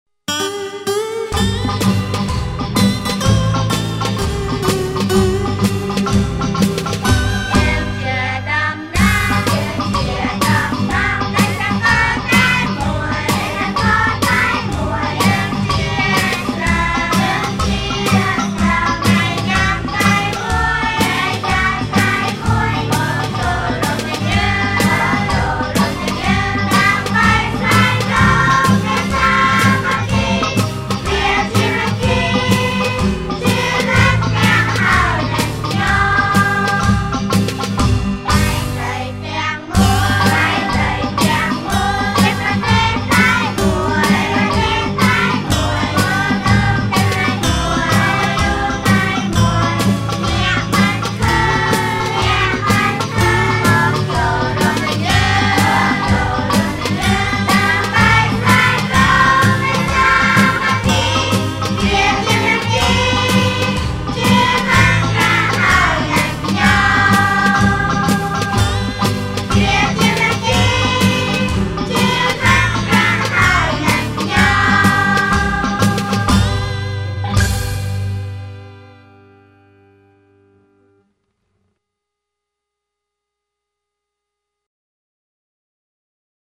Recorded in Phnom Penh, Cambodia (2003)
From a Hawaiian melody
Bahá’í Children's Songs